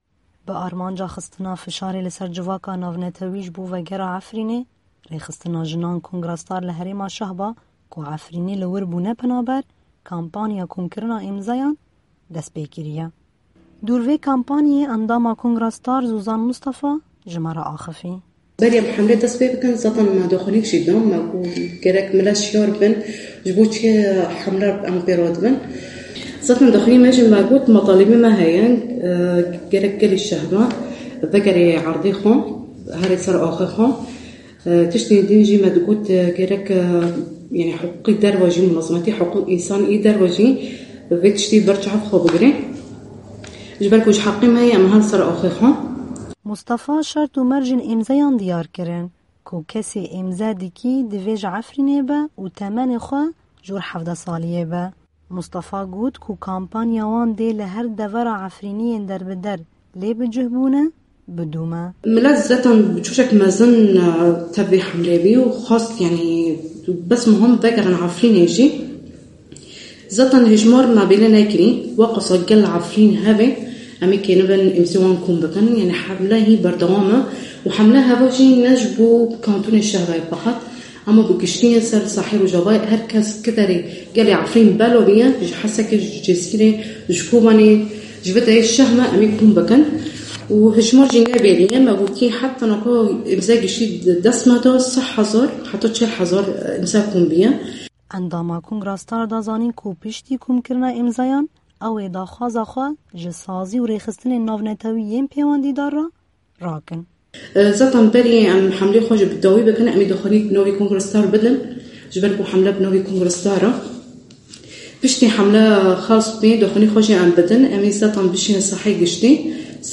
Raporta Efrîn-Vegera Efrînê